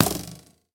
bowhit2.ogg